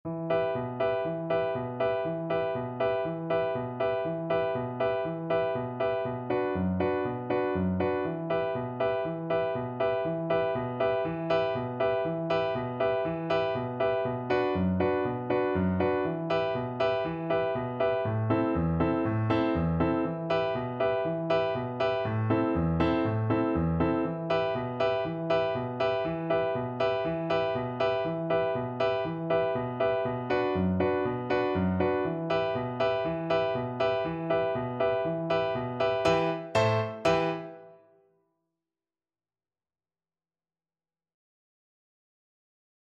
Allegro vivo (View more music marked Allegro)
4/4 (View more 4/4 Music)
World (View more World Recorder Music)